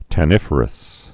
(tă-nĭfər-əs)